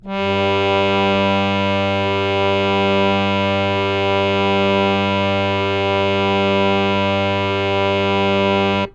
harmonium
G2.wav